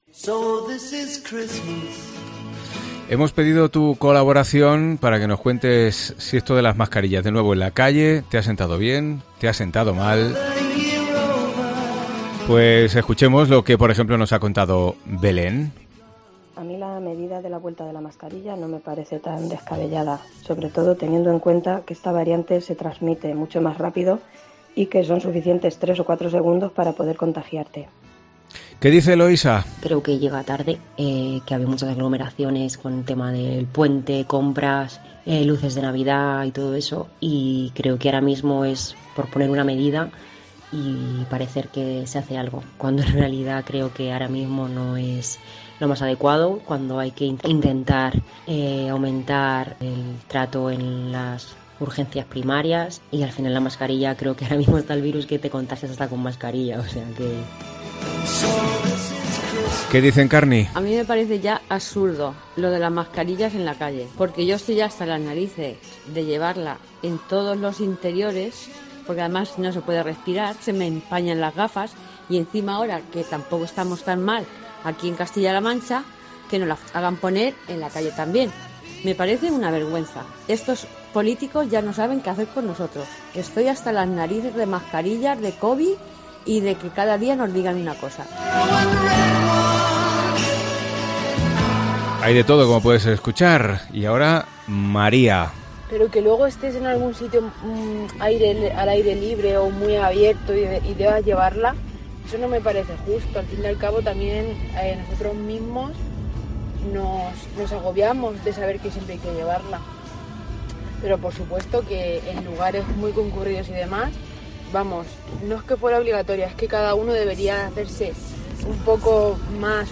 ENCUESTA COPE
Tantas opiniones como personas, aunque con matices interesantes cada una. Escucha el minisondeo que hemos hecho en Herrera en COPE Albacete